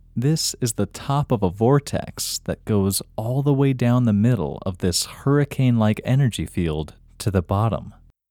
IN – Second Way – English Male 13